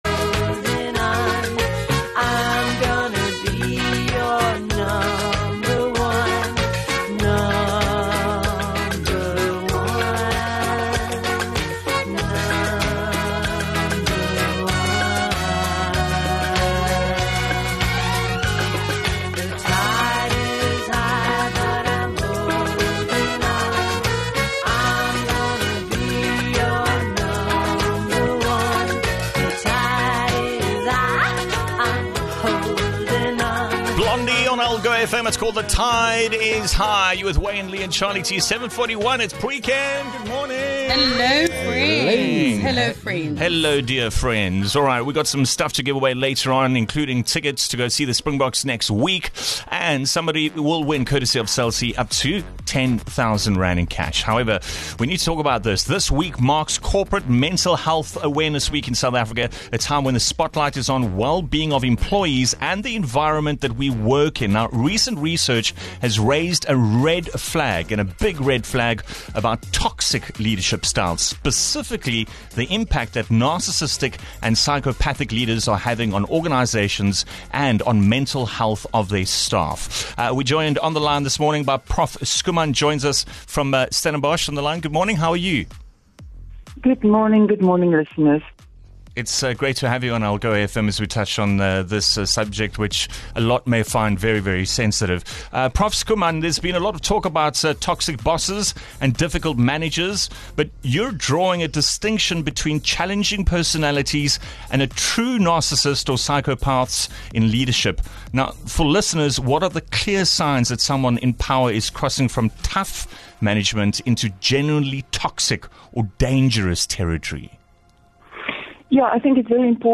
Expect thought provoking interviews, heartfelt stories that impact Algoa Country positively and laughter that will set your day off right.